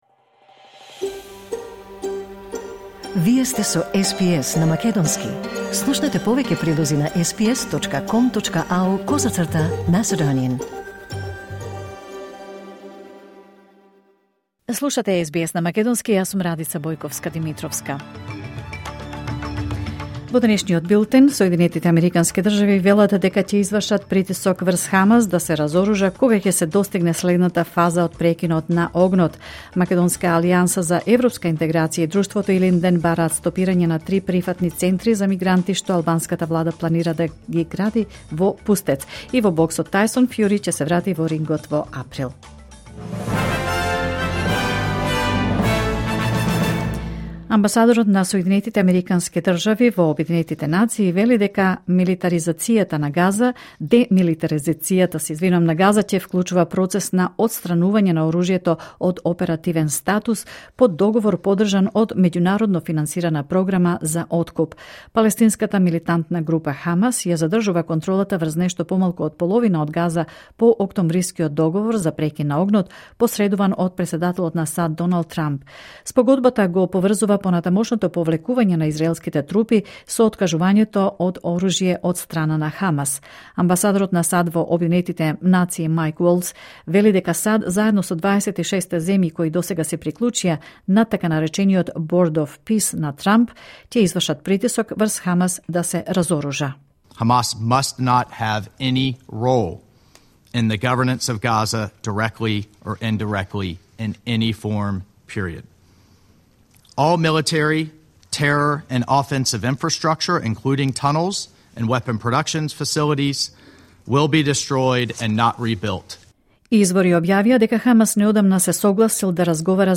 Вести на СБС на македонски 29 јануари 2026